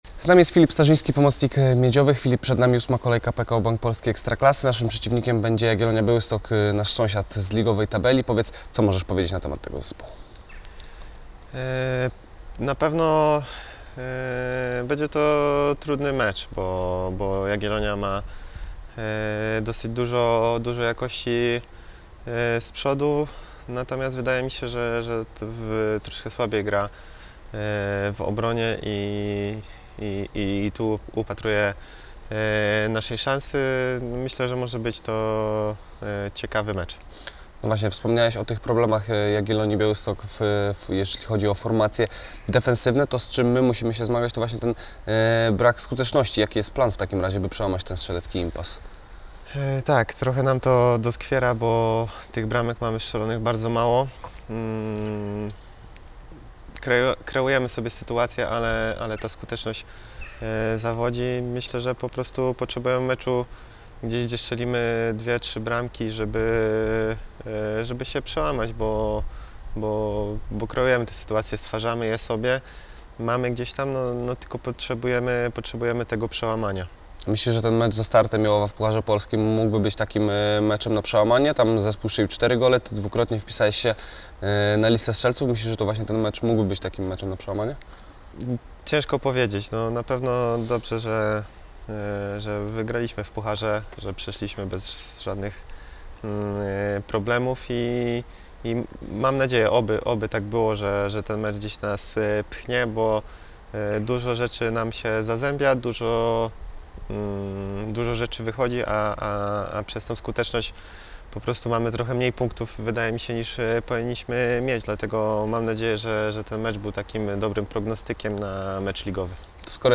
Przedmeczową rozmowę z Filipem Starzyńskim.